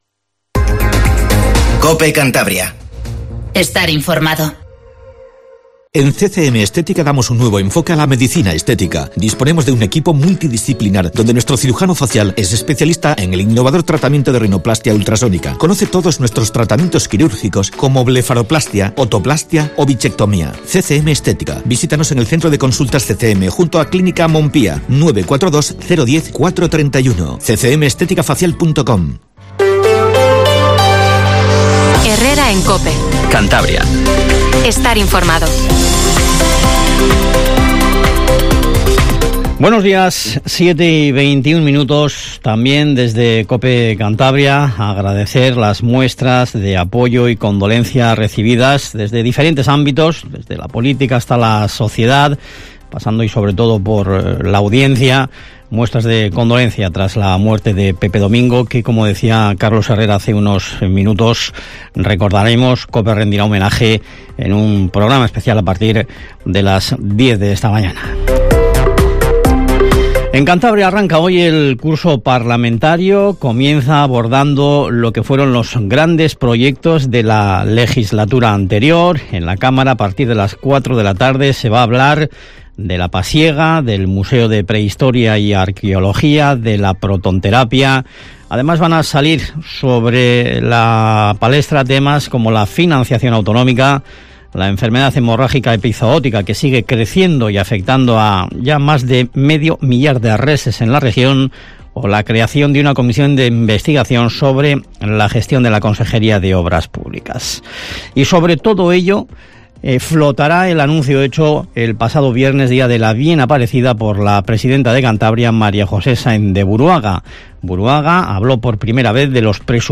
Informativo HERRERA en COPE CANTABRIA 07:20